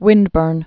(wĭndbûrn)